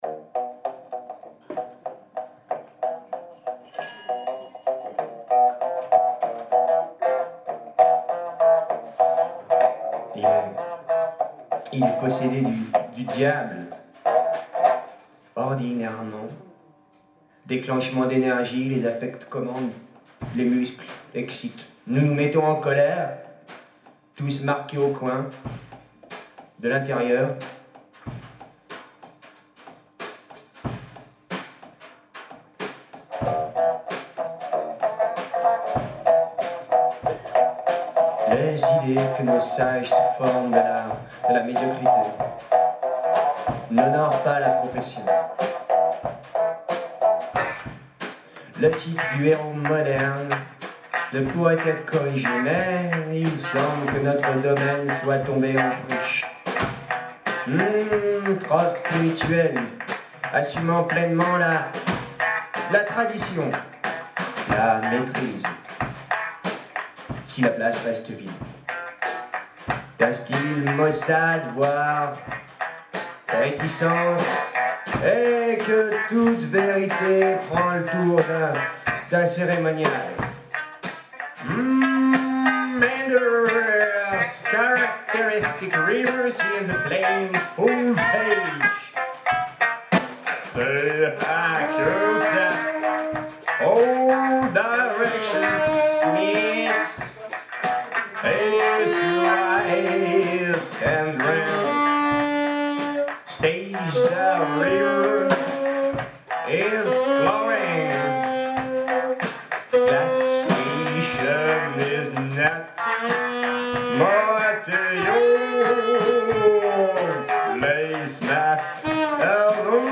Concert de soutien